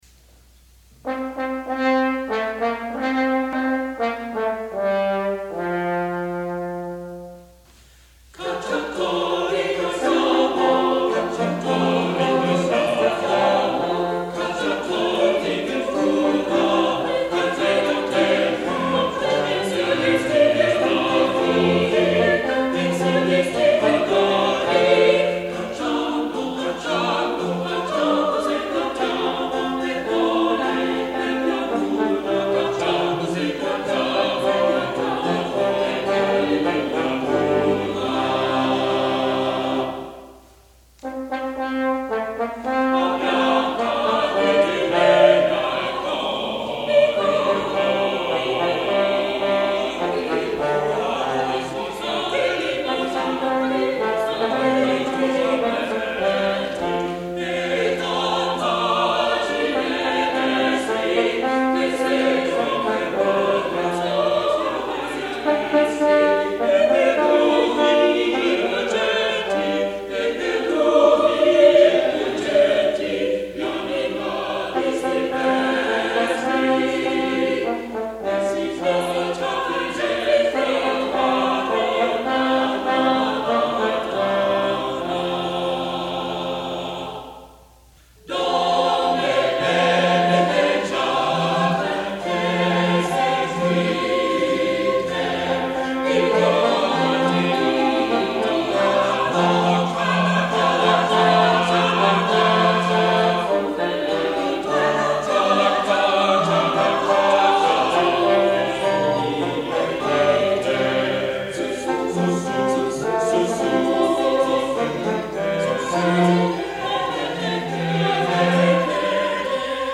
Although he was a church musician all his life he is best known for his ‘balletti’, which are light, direct, simple dance-songs that use nonsense syllables like “Fa-la-la”.